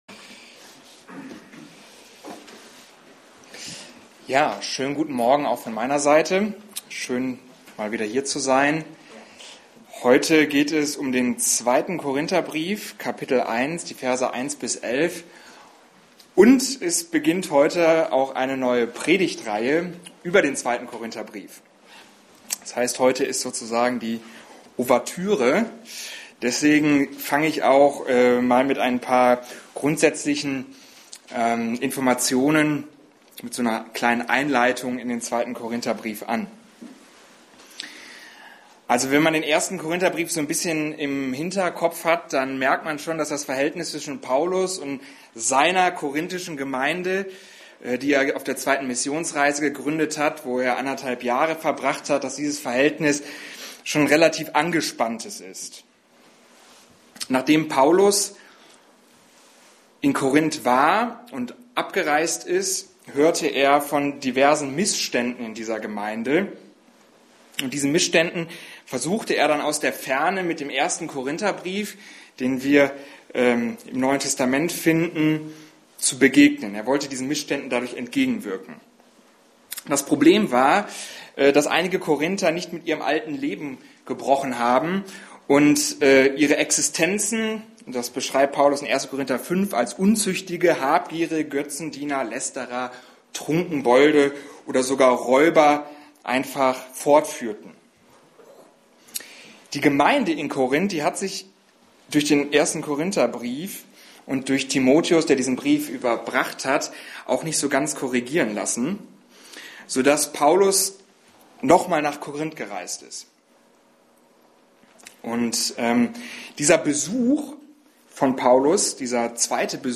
Passage: 1. Samuel 1, 1-28 Dienstart: Predigt